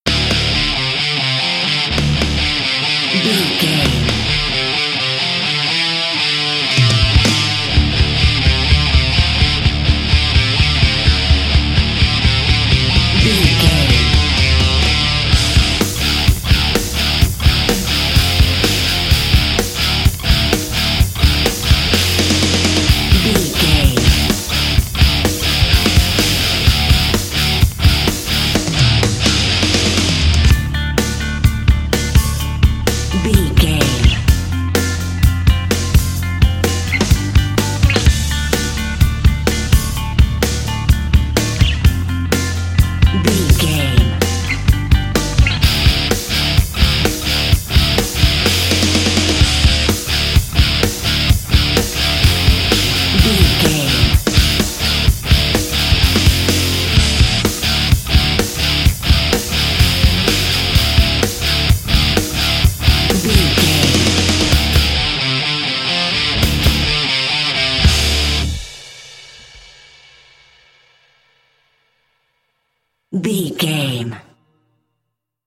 Epic / Action
Phrygian
drums
electric guitar
bass guitar
Sports Rock
hard rock
angry
lead guitar
aggressive
energetic
intense
nu metal
alternative metal